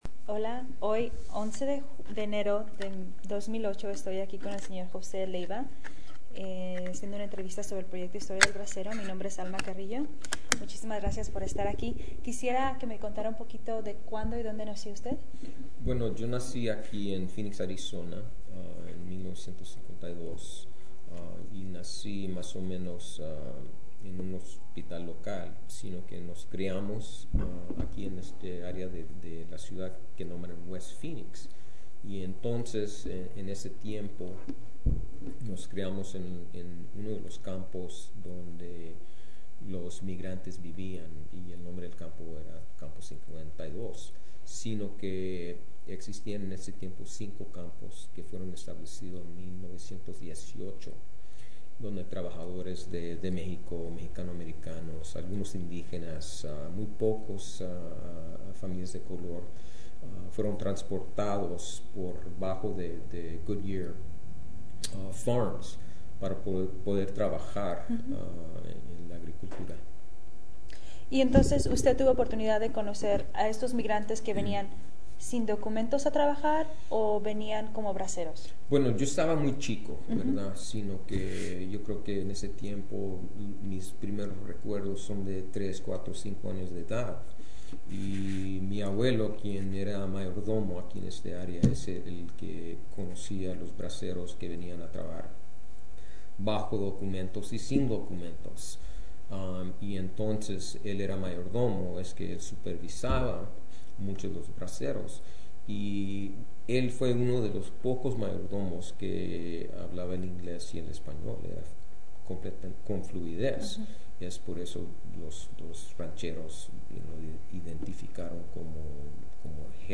Summary of Interview